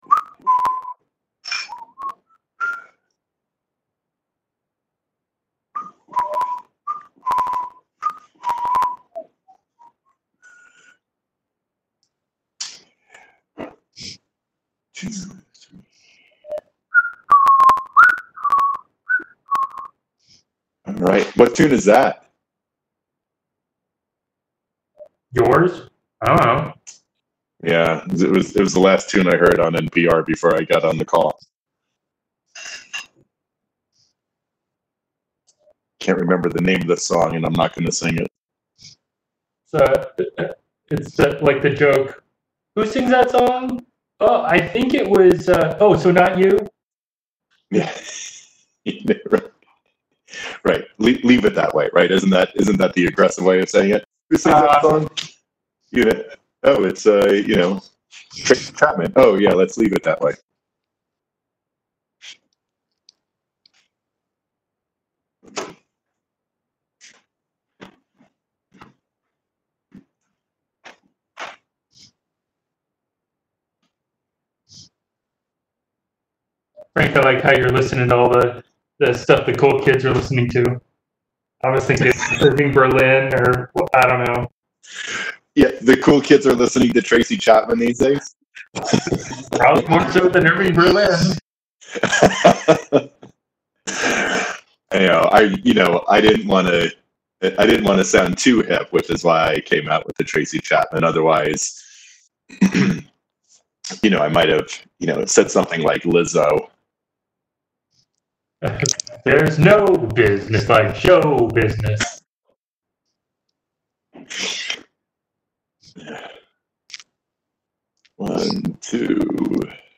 On June 11 2020 the steering committee for the East Central Area met .